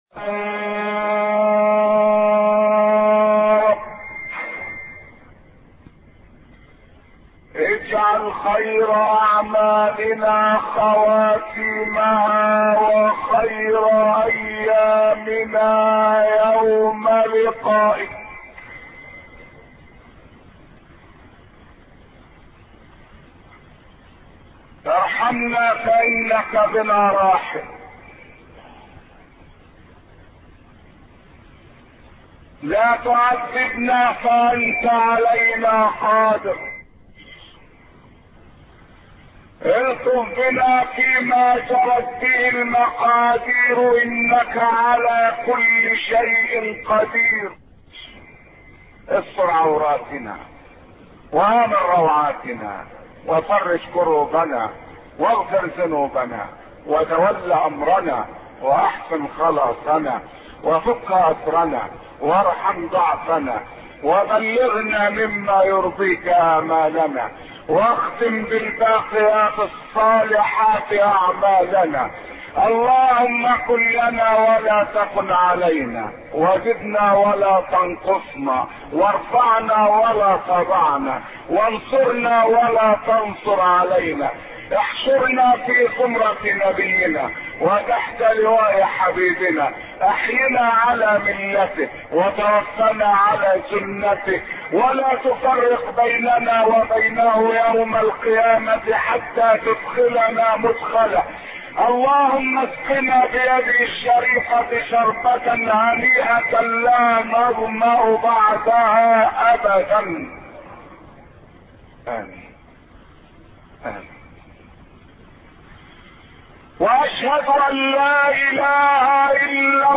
أرشيف الإسلام - ~ أرشيف صوتي لدروس وخطب ومحاضرات الشيخ عبد الحميد كشك